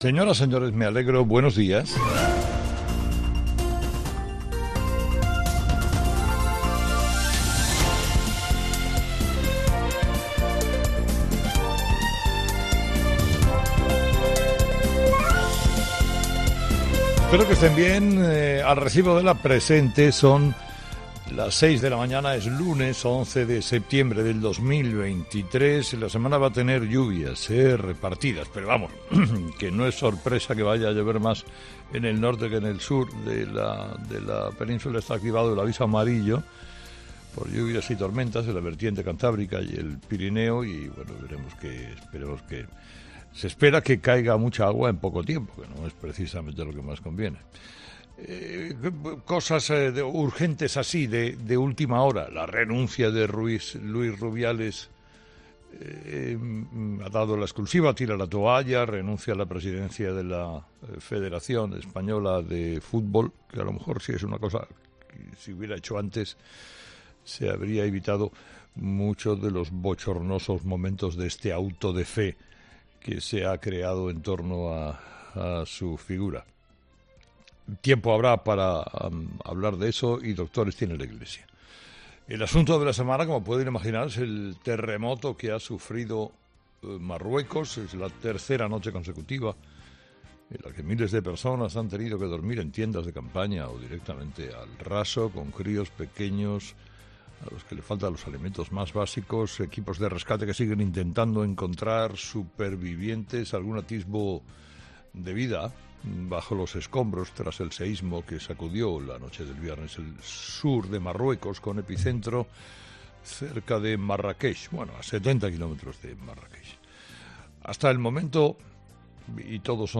Escucha el análisis de Carlos Herrera a las 06:00 h. en Herrera en COPE el lunes 11 de septiembre